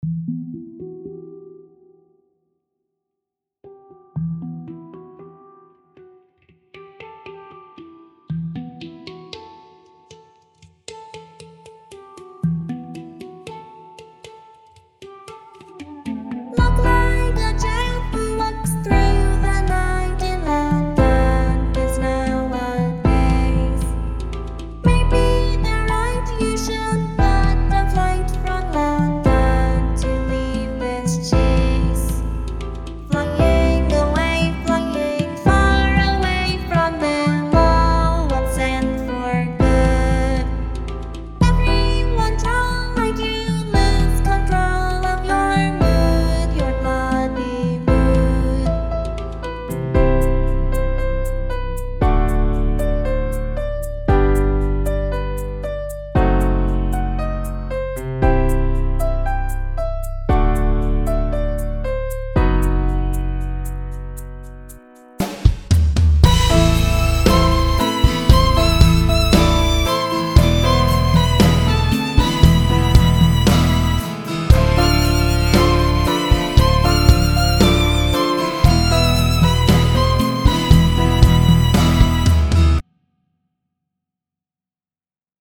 Такой набросок, возник спор с другом, годится ли туда ханг или нет. Там везде должен быть вокалоид и все такое, я пока не прописал, если что...